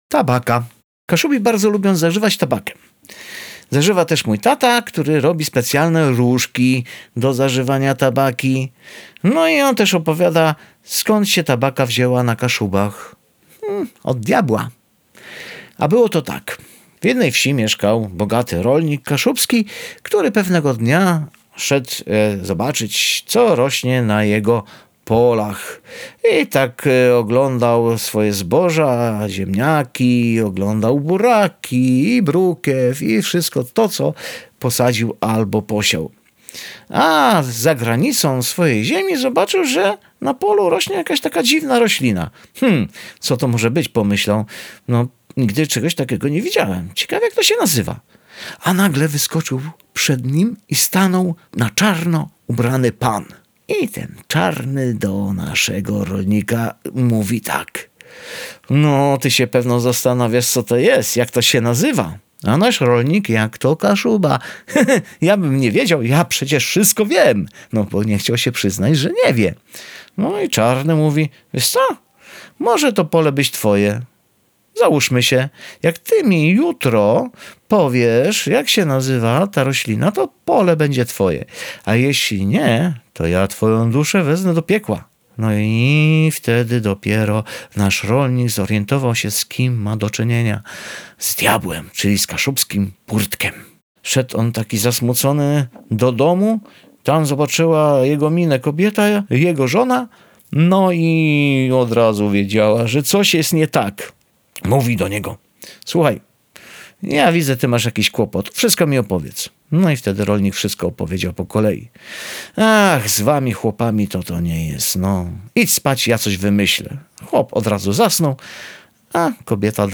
Bajka „Tabaka”.
nagranie w wersji polskojęzycznej